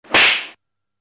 Slap Sound Button Sound Button
Description: Slap Sound Button
Slap sound button sound button is a short, punchy audio clip that people love using in memes, gaming streams, and reaction edits.